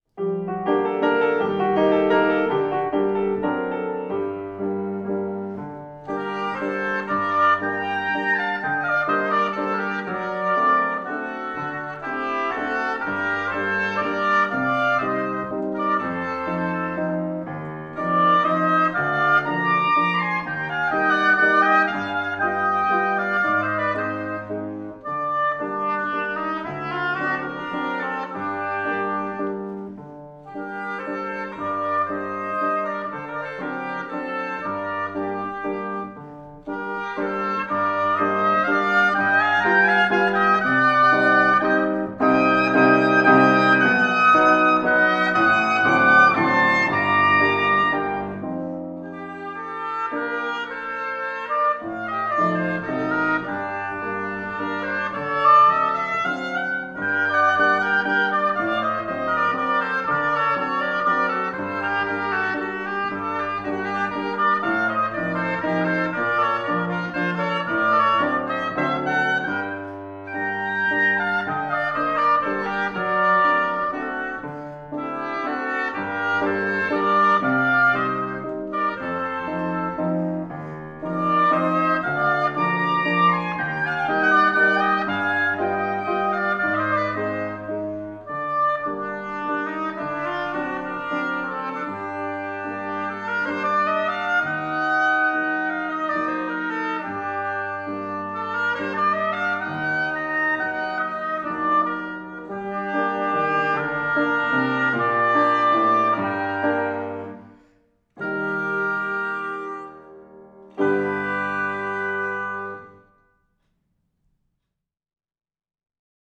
ein junges Oboen-Klaviertrio
Ein Walzer